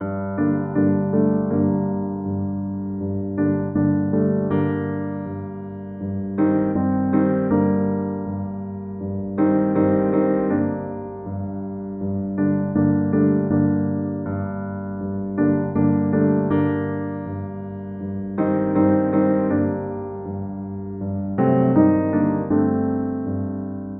Dark Keys 6 BPM 80.wav